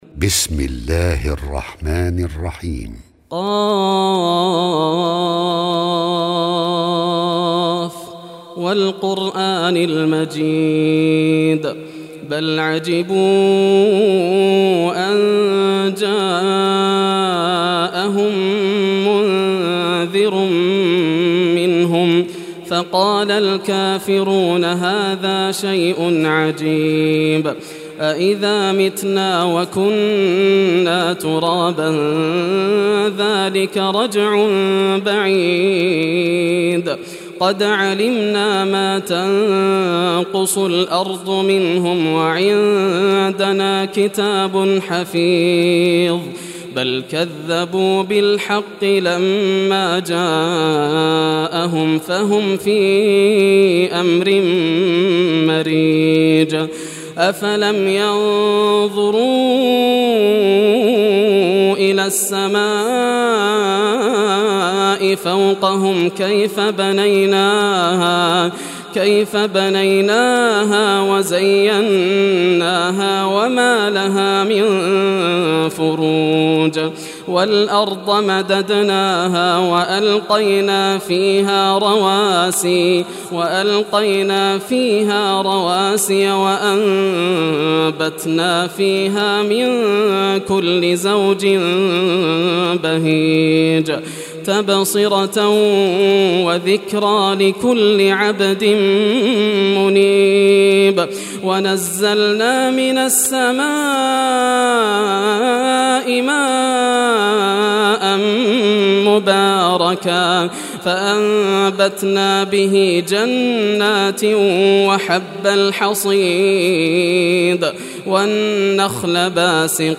Surah Qaf Recitation by Yasser al Dosari
Surah Qaf, listen or play online mp3 tilawat / recitation in Arabic in the beautiful voice of Sheikh Yasser al Dosari.